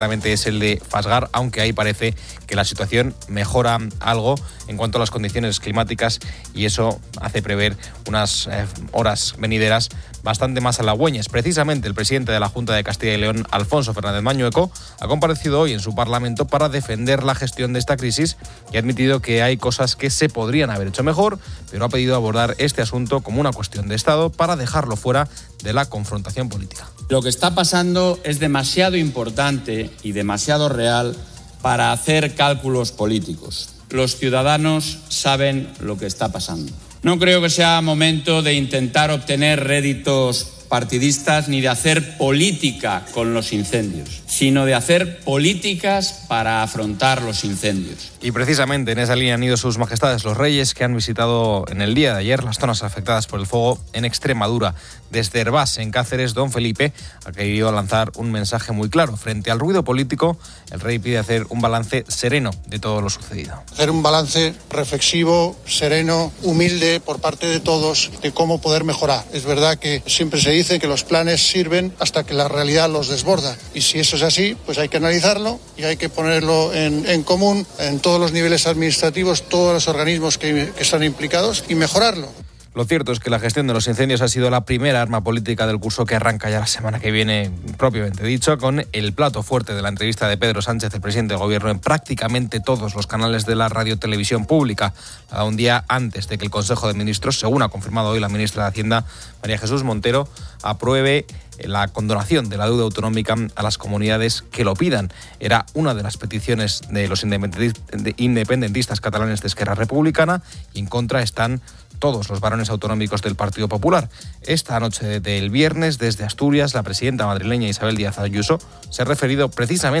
Un médico explica los síntomas, la diferencia entre artritis y artrosis, el impacto del estrés y desmiente mitos sobre estas dolencias. Finalmente, se aborda la propuesta de la Unión Europea para digitalizar los prospectos de medicamentos con códigos QR.